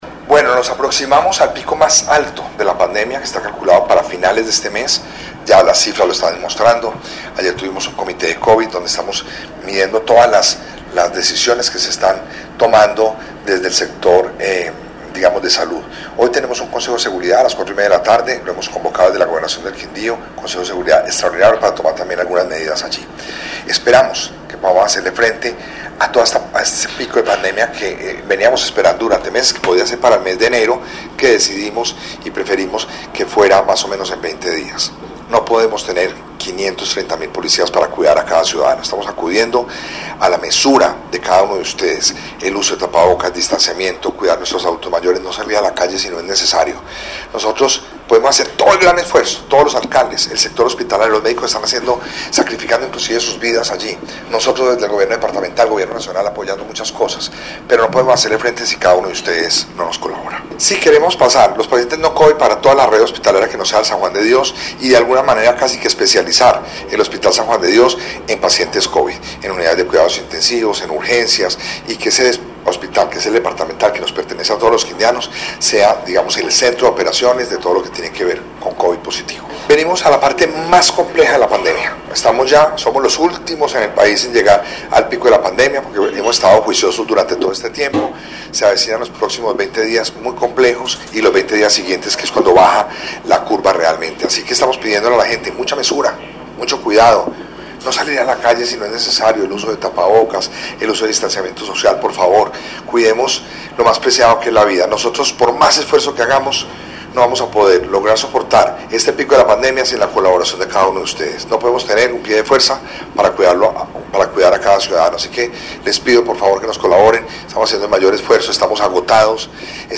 Audio: Yenny Alexandra Trujillo Alzate, Secretaria de Salud
Audio: Roberto Jairo Jaramillo Cárdenas, Gobernador del Quindío